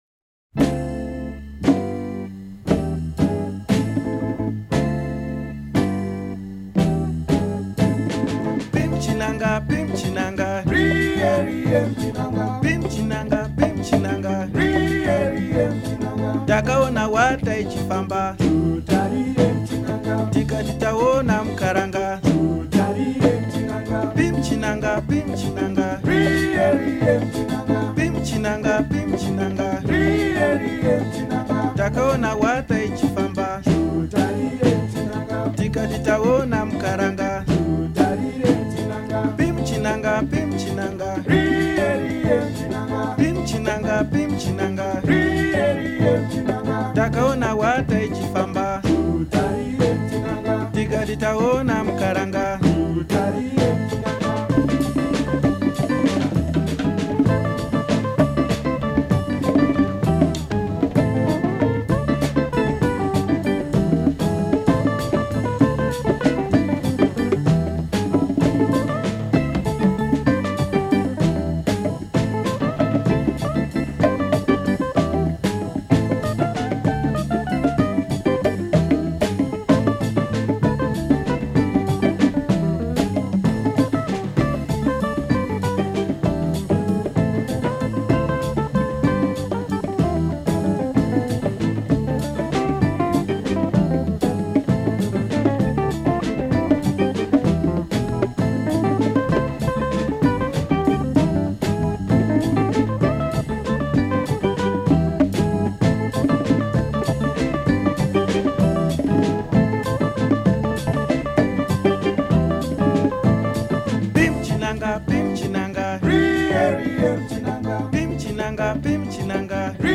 Beautiful Zambian Jazz/Rock LP.